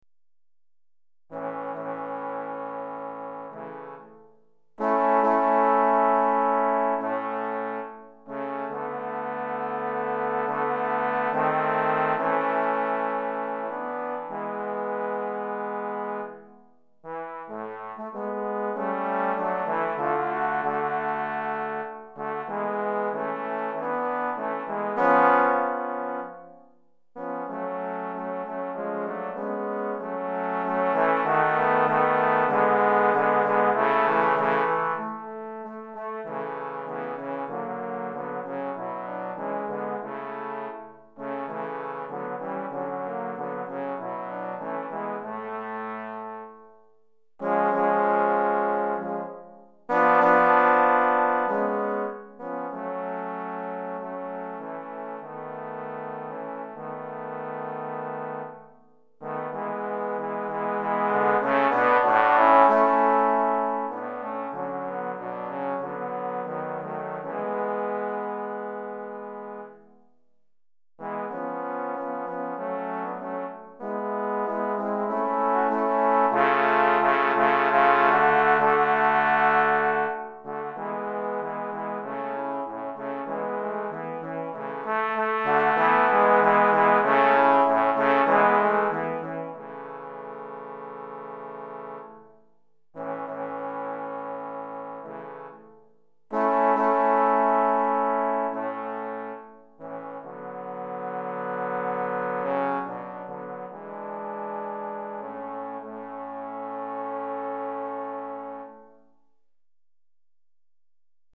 3 Trombones